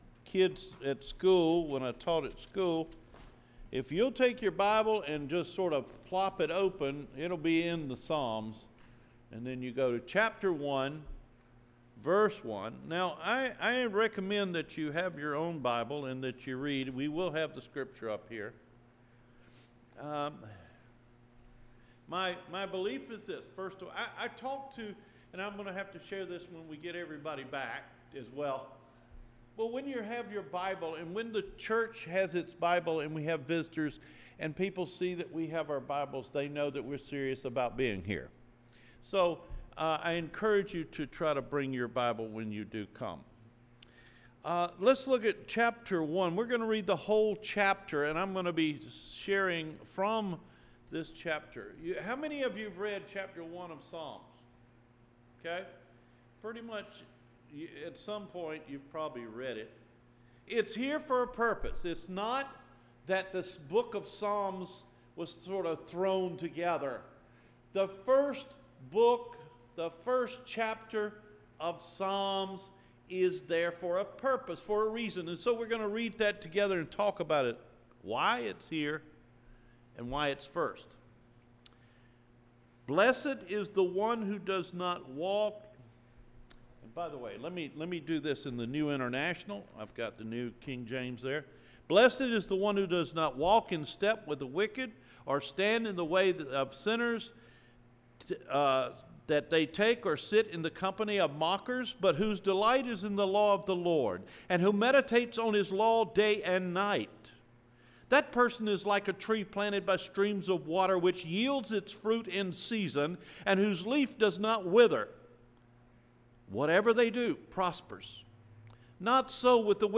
Recorded Sermons